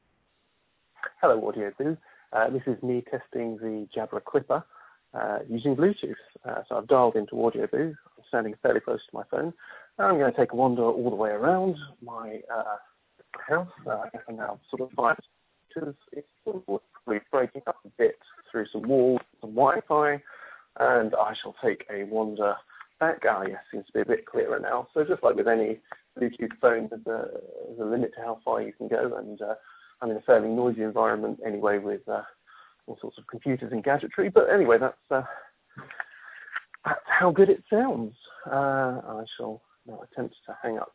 Phone call quality is very good - take a listen to this AudioBoo.